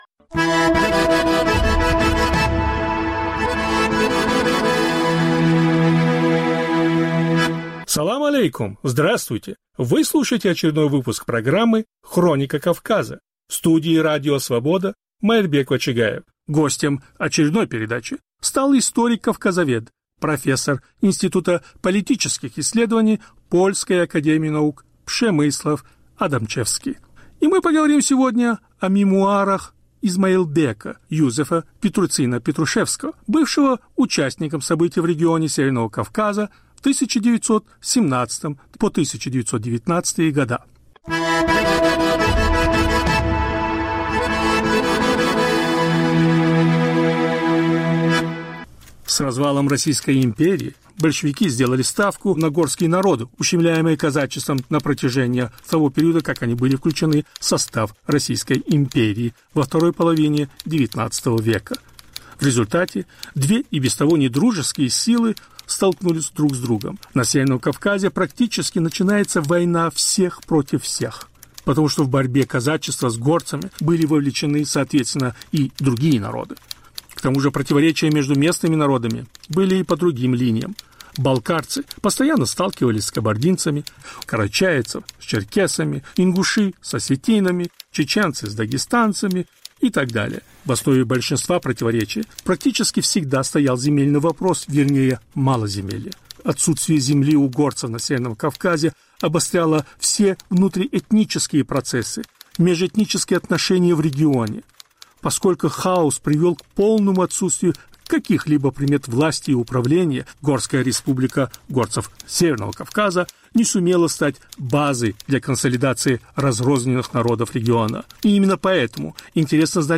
Повтор эфира от 12 ноября 2023 года.